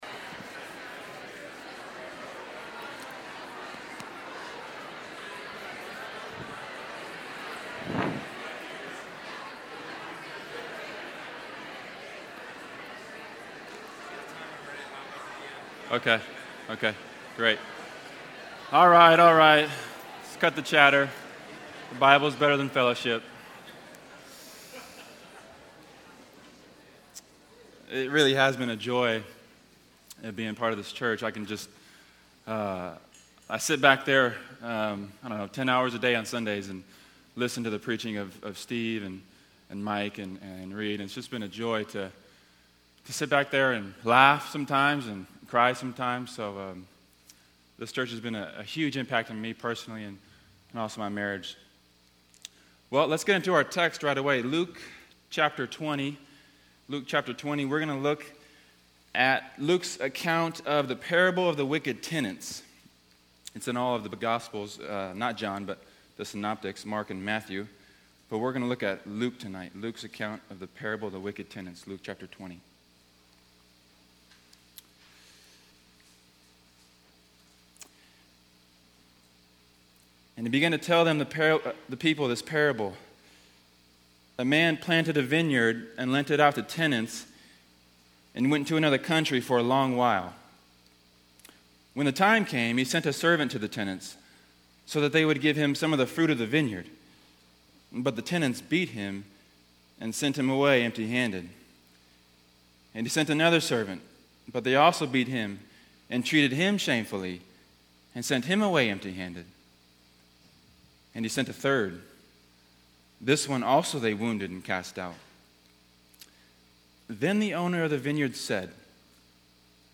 The Wicked Tenants – 5pm Service